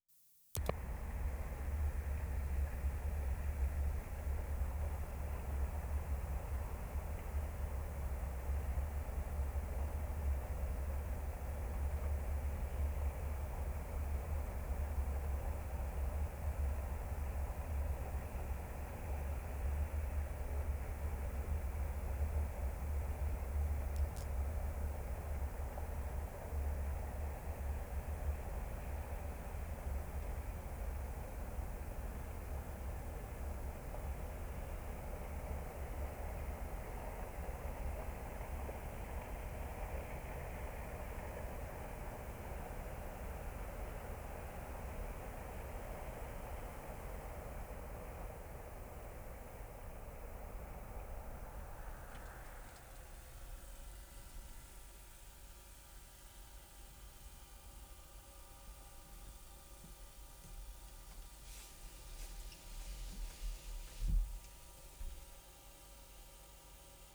WORLD SOUNDSCAPE PROJECT TAPE LIBRARY
2. City rumble at start. At 0'50" microphones are moved inside car. Van heater becomes foreground. Quite a contrast.